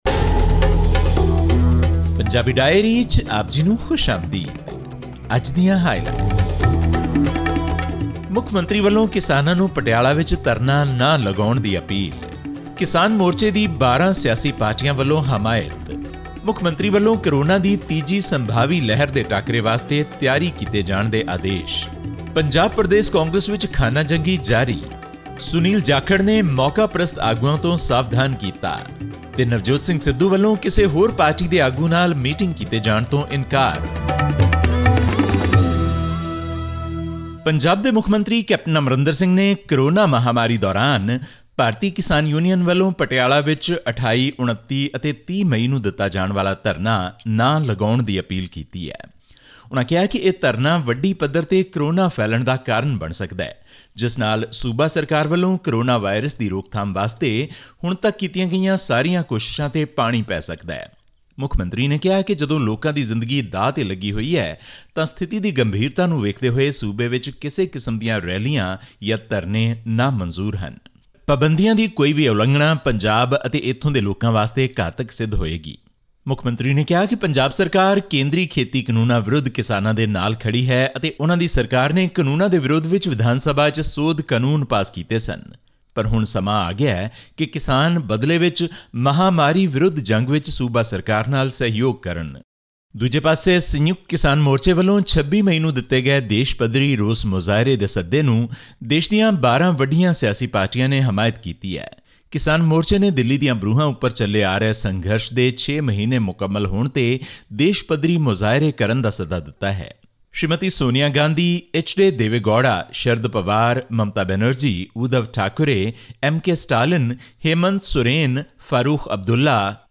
Punjab Chief Minister Captain Amarinder Singh has ordered preparations against a possible third wave of Coronavirus amidst concerns about its impact on children. This and more in our weekly news segment from Punjab.